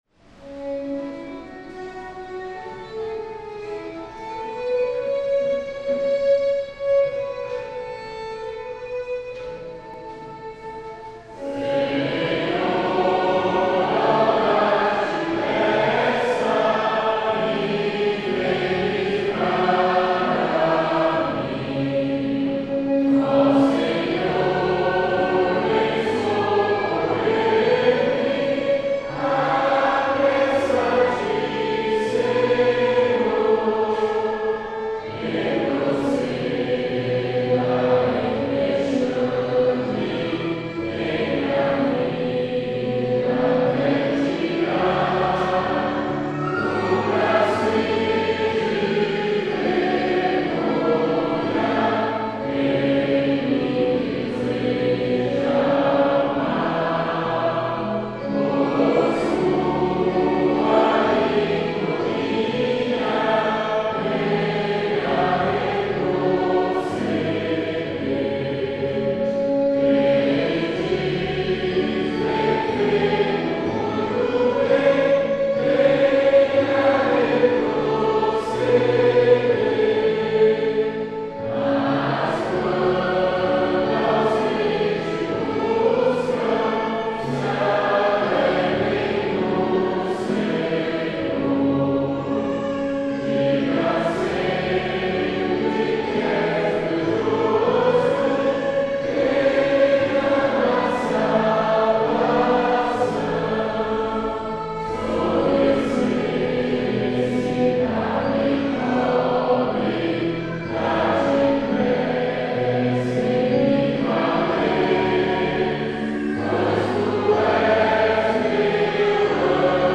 salmo_70B_cantado.mp3